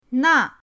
na4.mp3